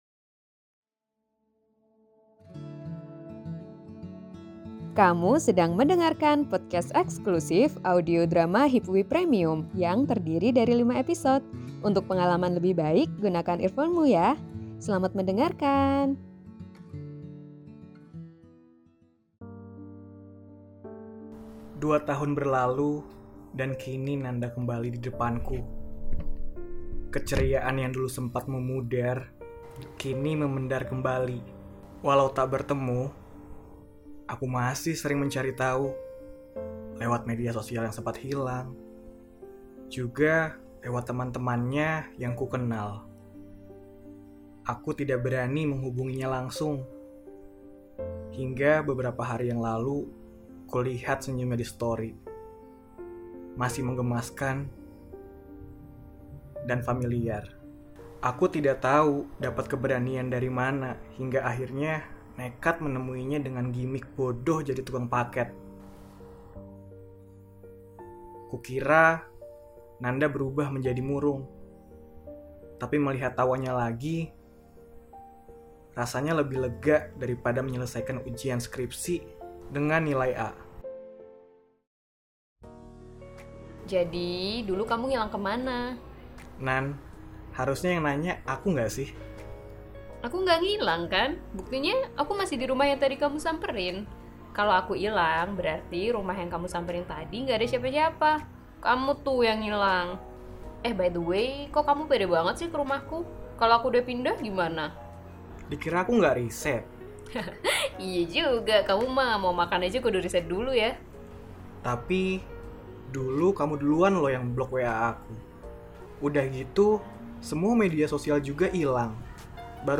[Audio Drama] Sebatas Kembali – Episode 5